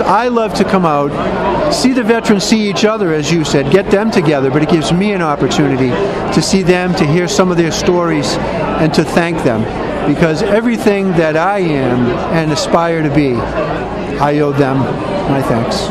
Plymouth County Sheriff Joe McDonald says it’s hard to imagine our present day world and freedoms without the contributions of our veterans.